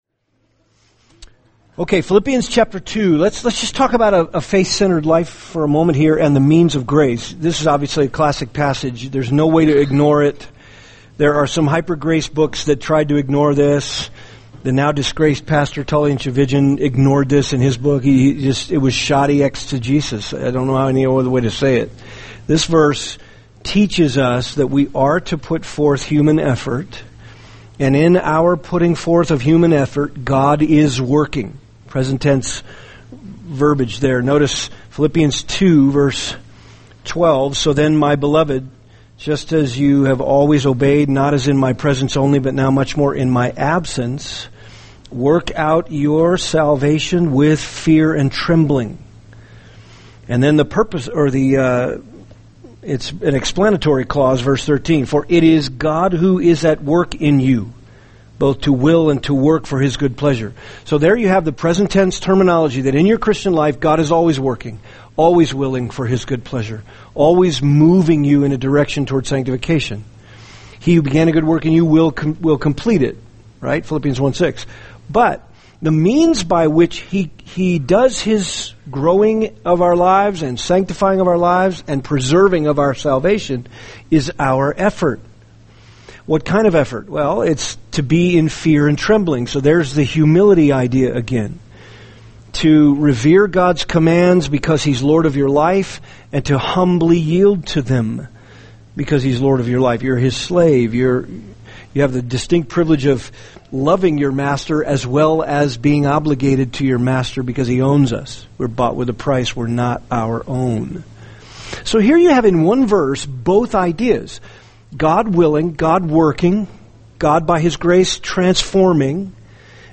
[seminar] Trust & Obey: Faith, Feelings, and Spiritual Growth (2 – Faith-Centered Life) | Cornerstone Church - Jackson Hole